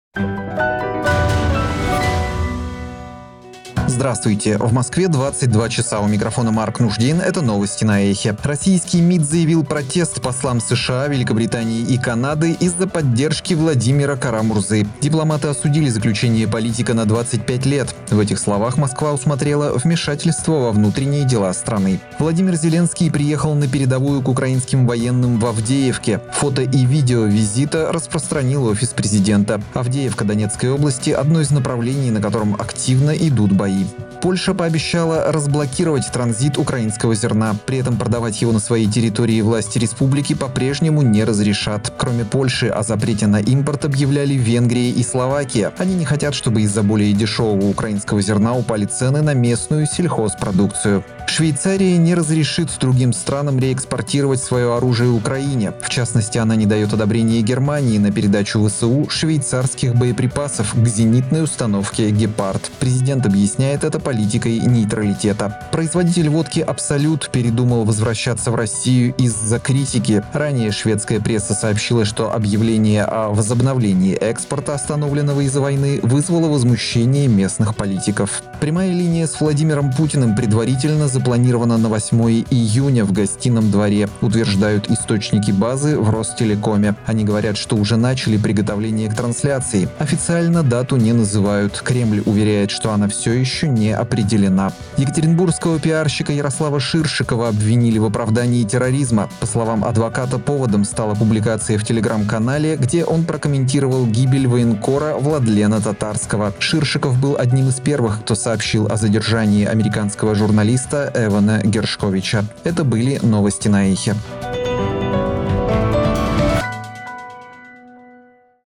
Слушайте свежий выпуск новостей «Эха»…
Новости